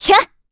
Valance-Vox_Attack7_kr.wav